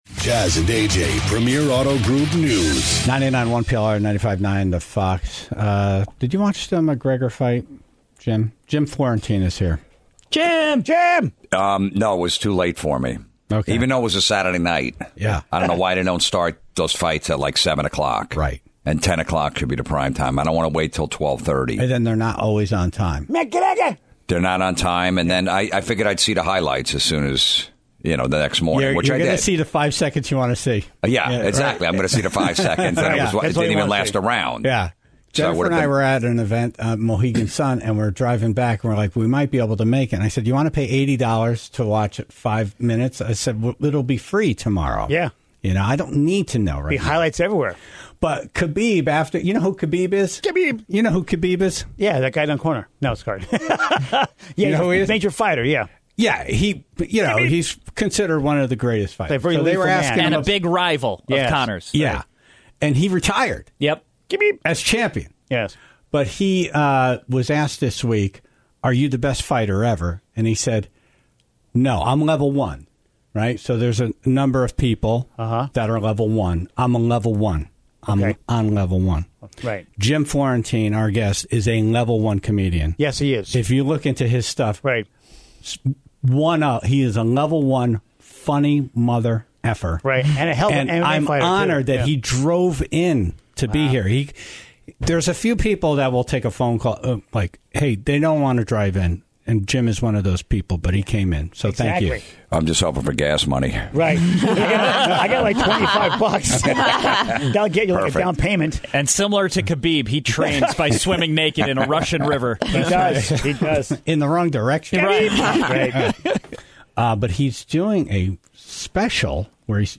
Jim commented on his Wikipedia bio, and then the Tribe called in their fun facts about Jim. One caller admitted to skinny dipping with Jim and some friends years ago.